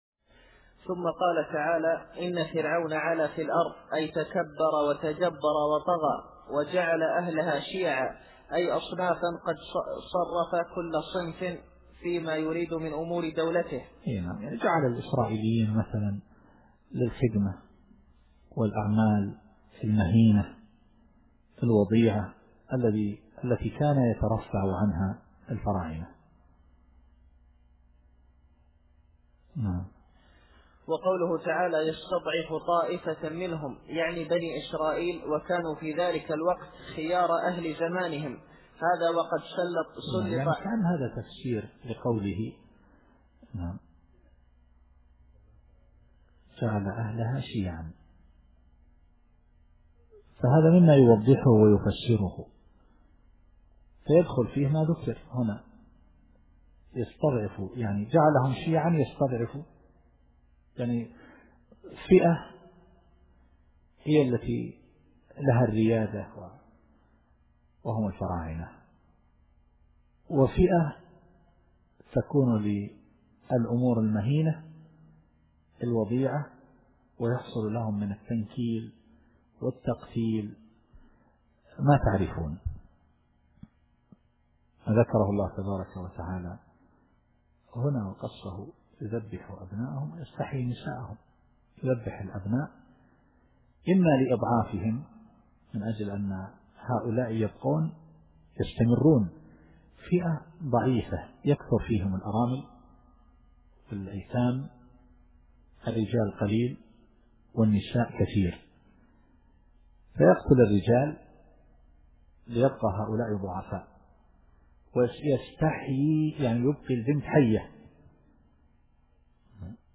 التفسير الصوتي [القصص / 6]